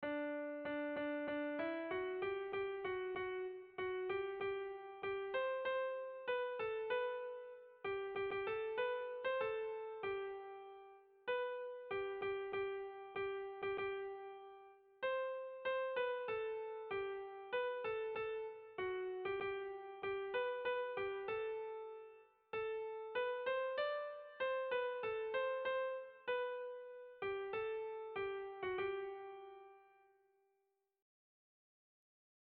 Kontakizunezkoa
Muxika < Busturialdea < Bizkaia < Euskal Herria
Zortziko txikia (hg) / Lau puntuko txikia (ip)
ABDE